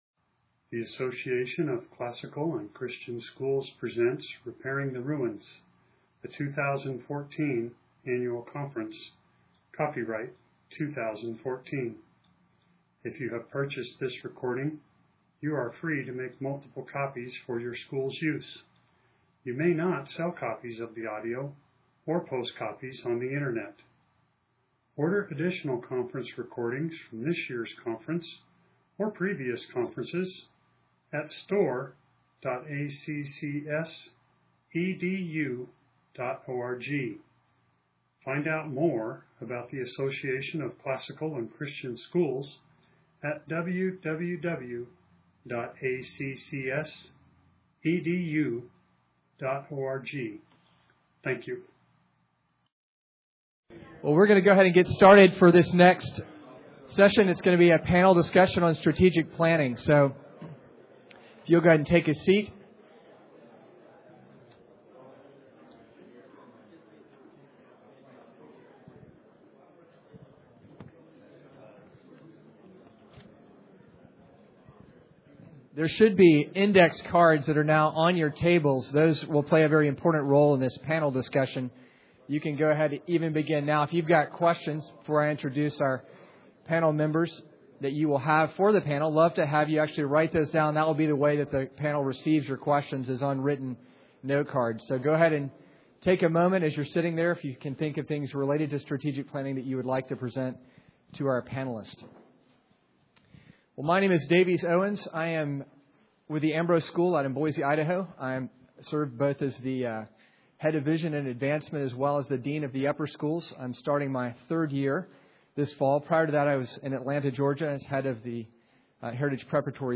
2014 Leaders Day Talk | 0:47:15 | Fundraising & Development, Marketing & Growth